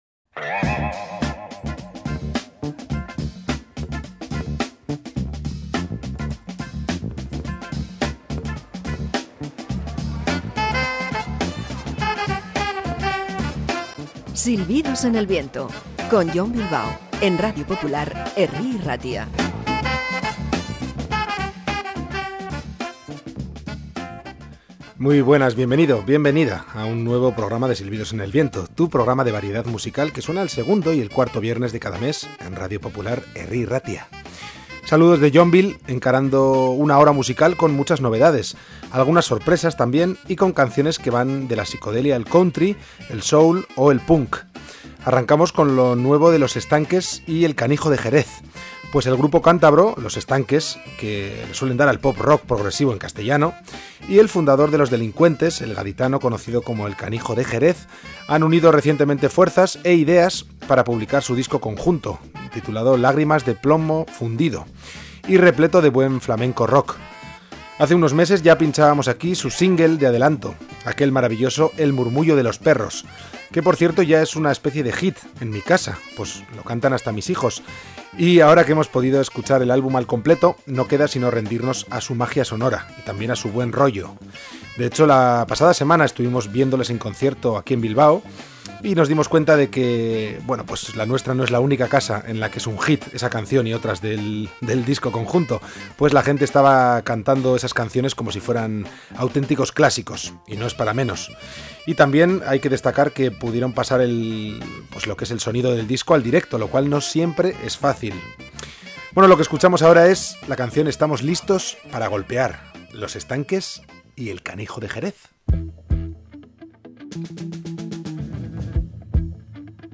Canciones recién llegadas de muy diversos estilos